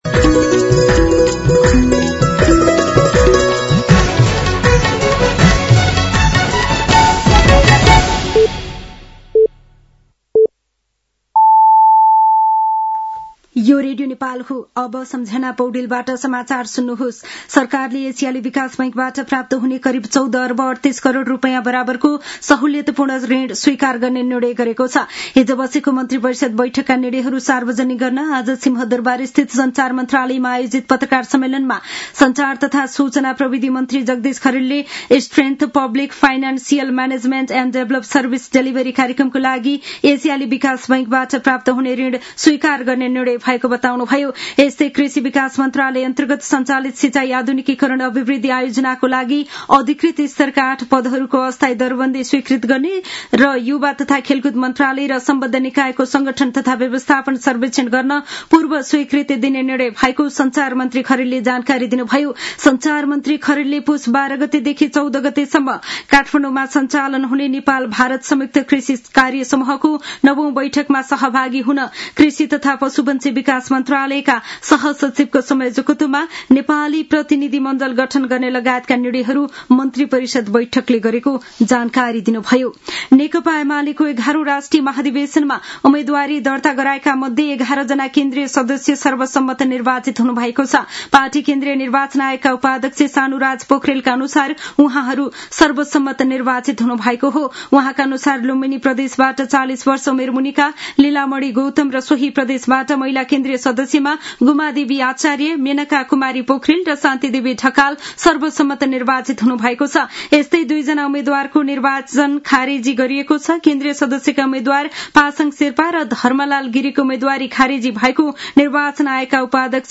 साँझ ५ बजेको नेपाली समाचार : १ पुष , २०८२